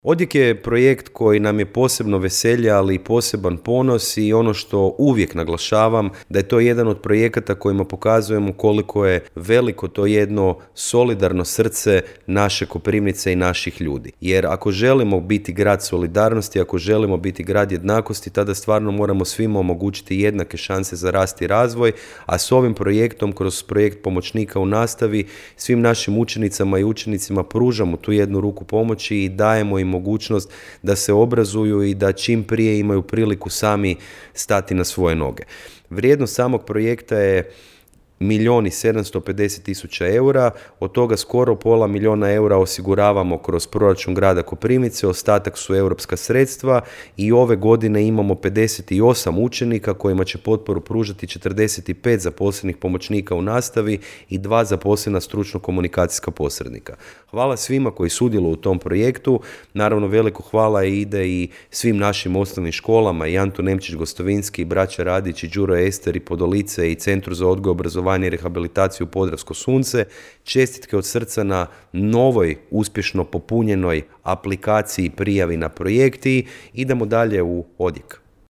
– poručio je gradonačelnik Grada Koprivnice Mišel Jakšić.